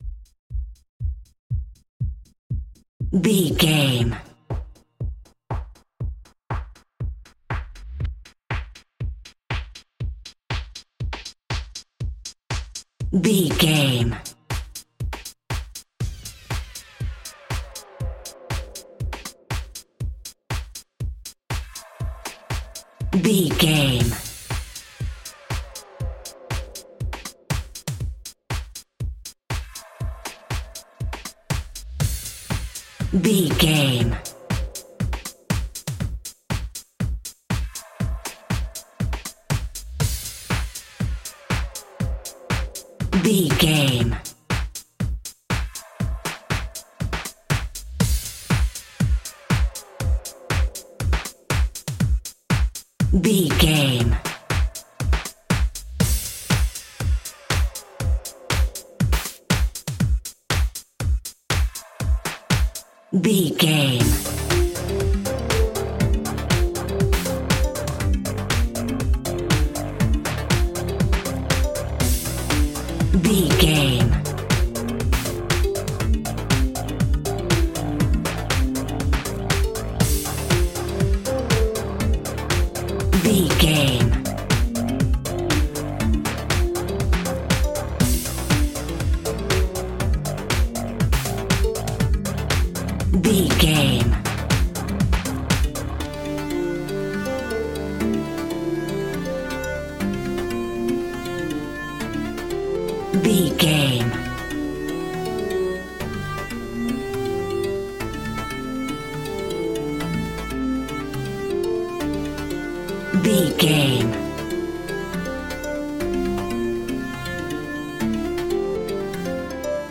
Aeolian/Minor
high tech
uplifting
futuristic
hypnotic
industrial
dreamy
drum machine
synthesiser
techno
electro house
electronic